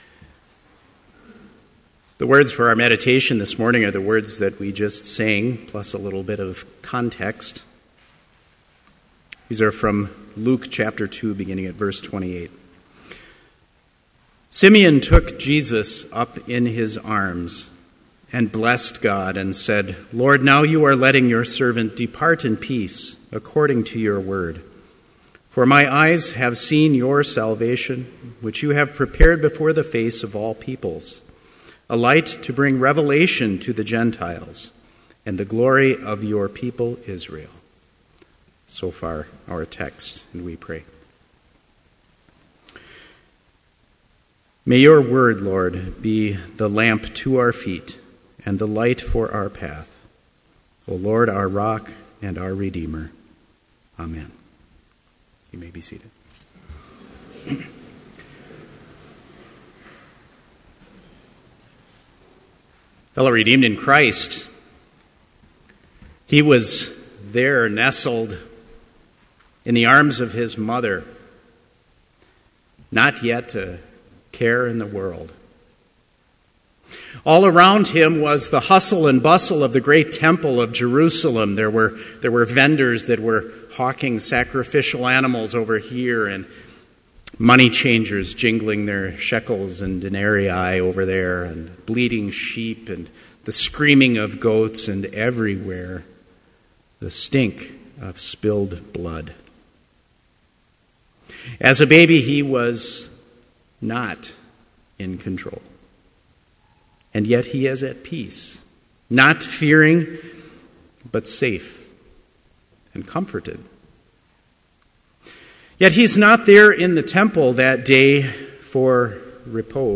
Chapel worship service in BLC's Trinity Chapel
Complete service audio for Chapel - November 12, 2014